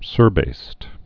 (sûrbāst)